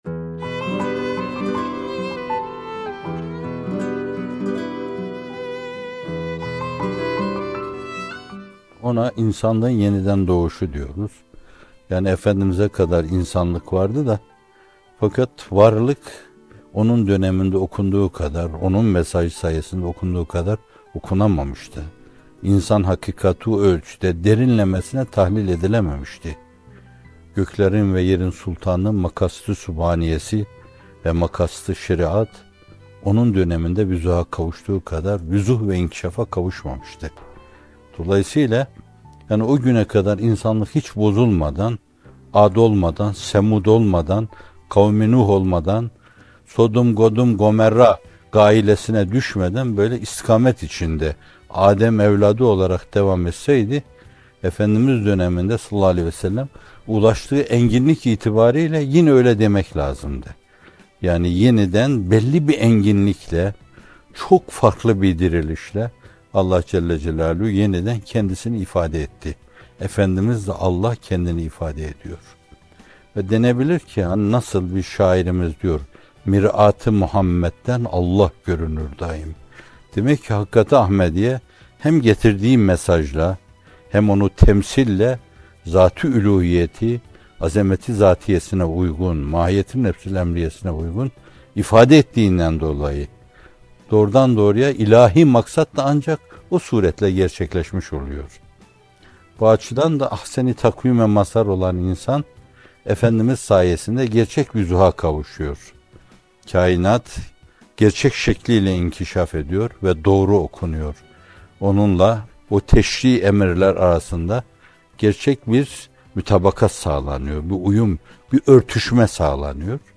Bir Nefes (89) – Kutlu Doğum İnsanlığın Yeniden Dirilişi - Fethullah Gülen Hocaefendi'nin Sohbetleri